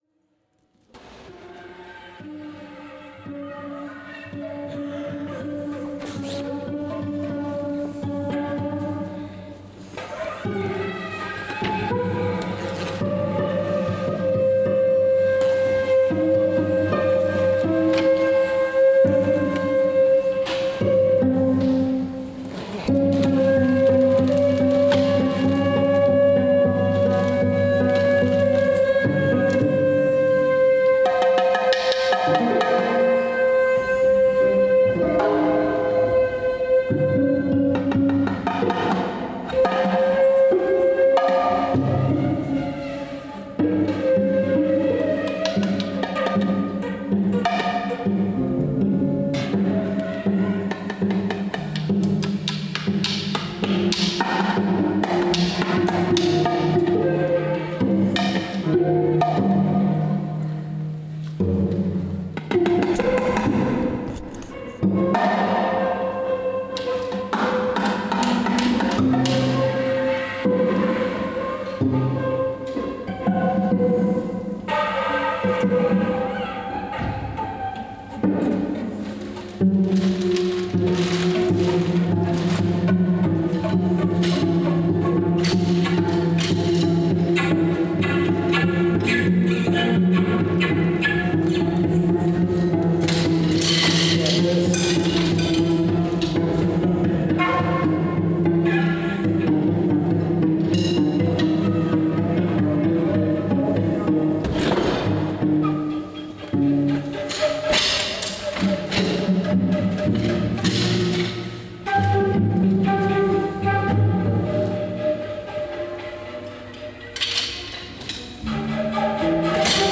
portuguese free jazz/avant garde ensemble
plays 100% improv